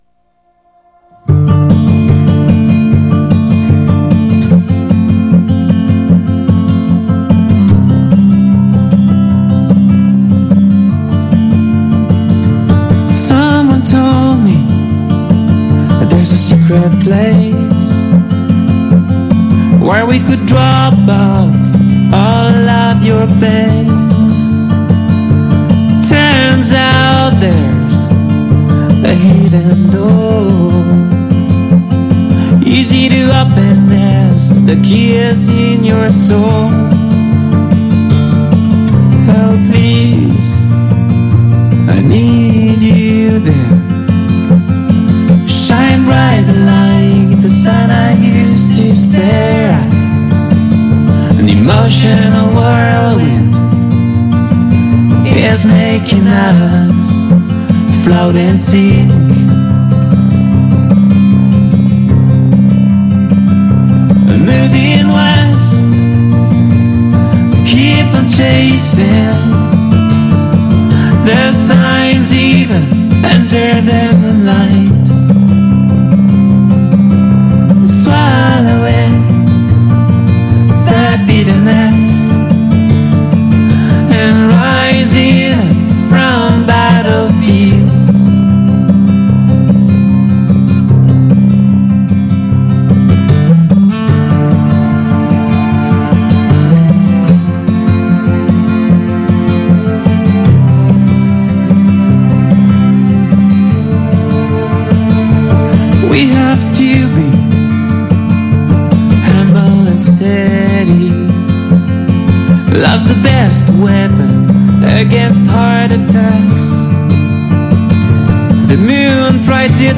pubblicata come podcast su Radio Città Aperta il 26/9/22 ma registrata in occasione dei Glory Days in Rimini 2022.